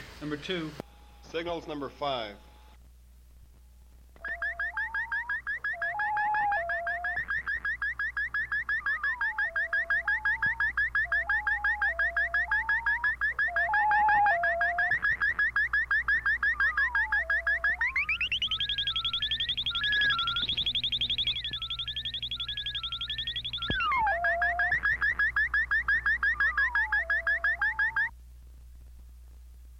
复古电子合成器和科幻 " G3412六个低音符
深深的隆隆声。 这些是20世纪30年代和20世纪30年代原始硝酸盐光学好莱坞声音效果的高质量副本。
我已将它们数字化以便保存，但它们尚未恢复并且有一些噪音。
标签： 合成器 音调 科幻 光学 经典
声道立体声